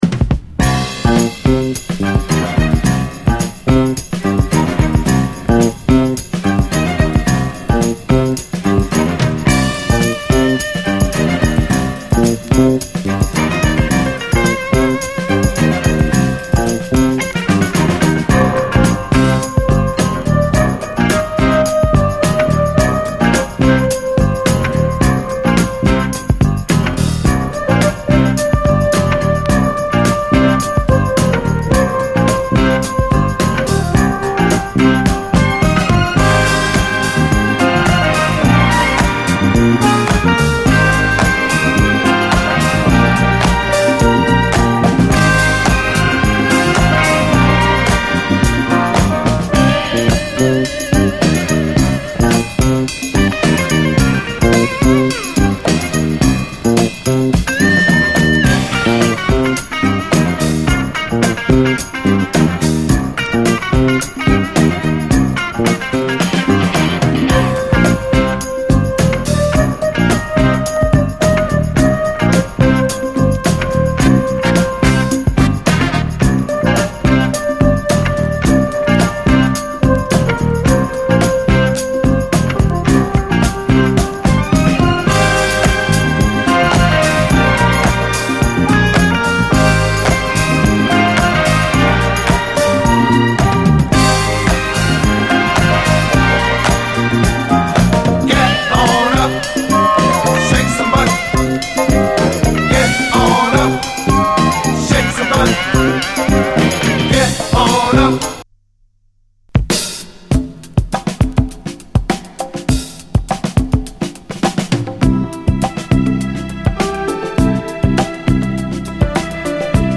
ズッシリと重いファンク